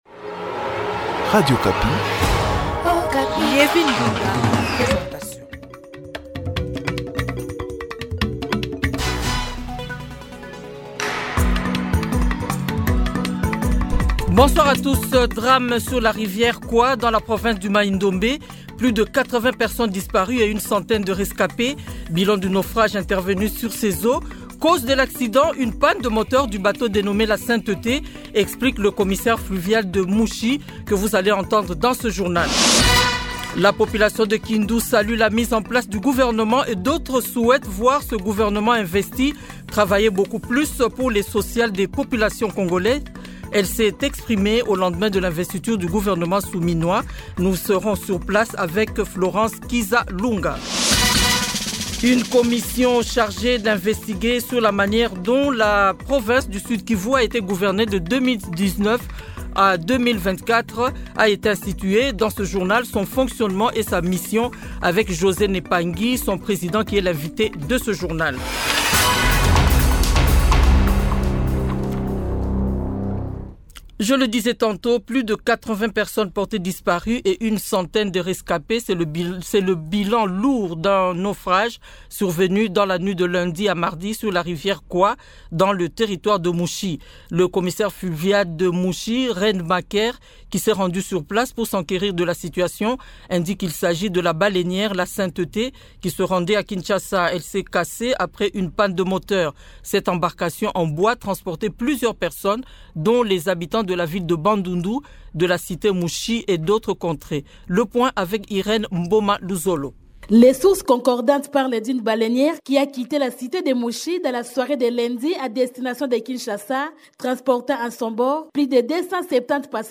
Journal de 15 heures